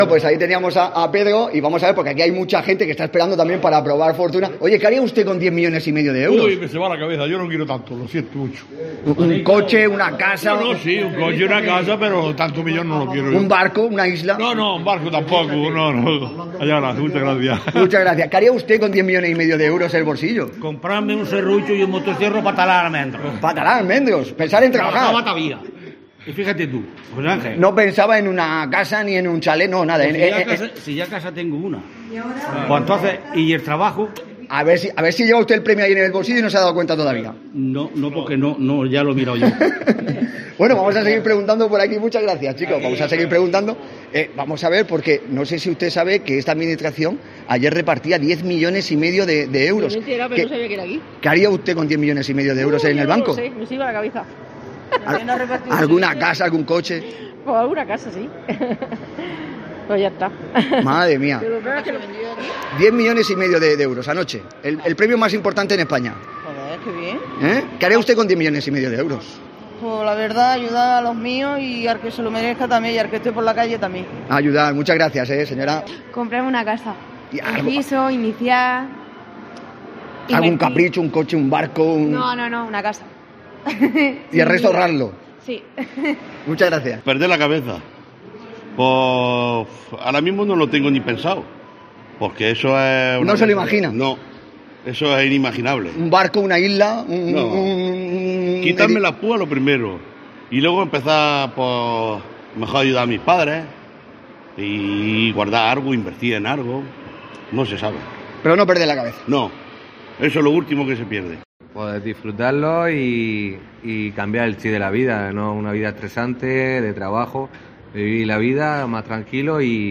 En COPE hemos preguntado a los lorquinos que harían con 11 millones de euros en el bolsillo.